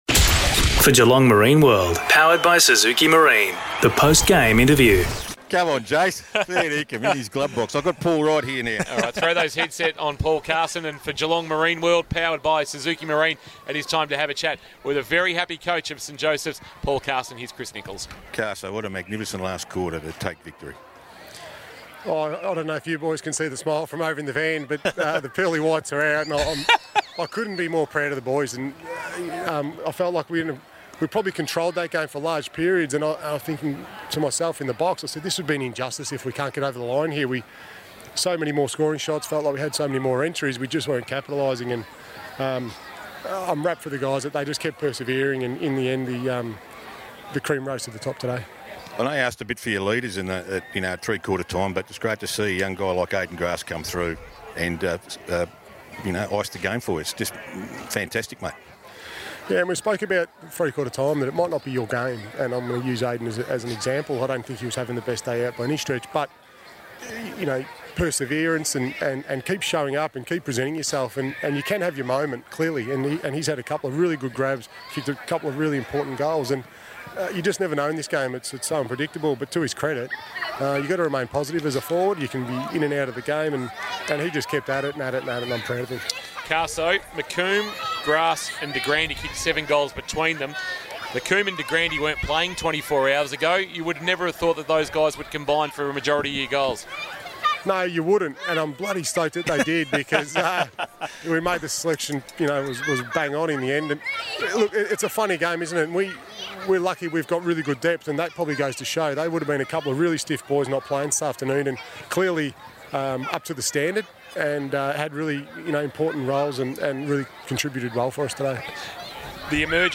2022 - GFL ROUND 3 - ST JOSEPH'S vs. SOUTH BARWON: Post-match Interview